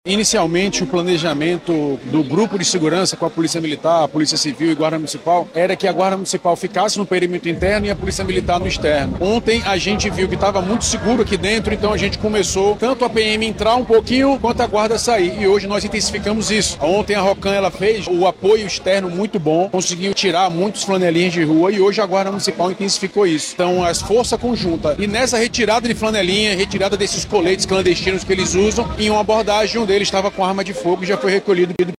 Neste domingo 07/09, antes da última noite do SouManaus, o secretário Municipal de Segurança Pública, Alberto Siqueira Neto, explicou que a atuação das Forças de Segurança foram alternadas para aumentar a efetividade em todos os perímetros.